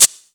VEE Open Hihat 105.wav